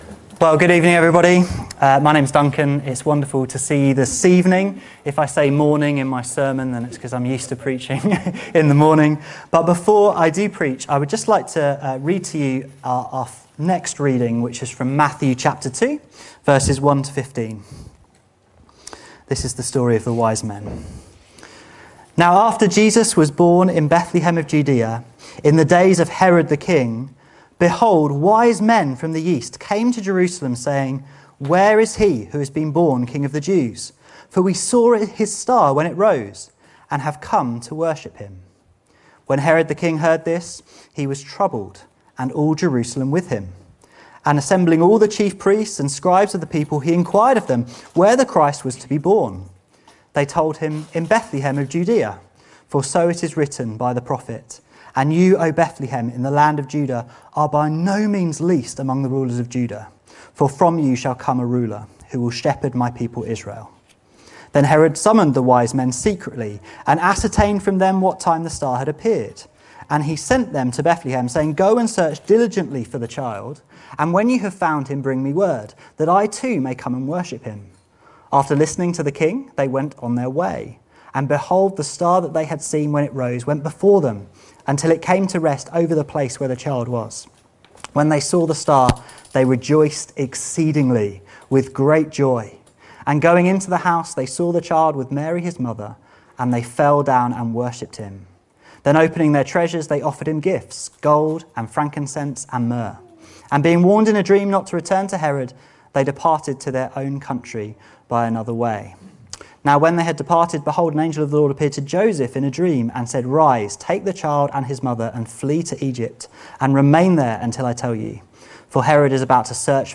Great Joy at Christmas | Carol Service
This sermon proclaims that the Christmas story is a cause for great joy!